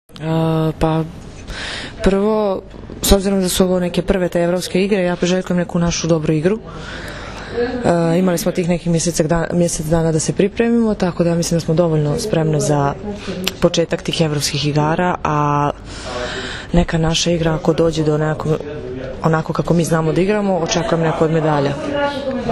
IZJAVA SILVIJE POPOVIĆ